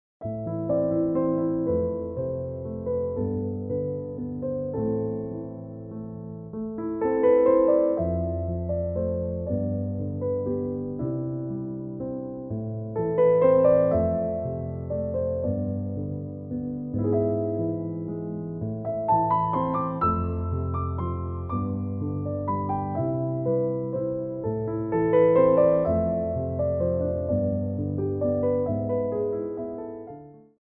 33 Tracks for Ballet Class.
Slow Tendus
4/4 (16x8)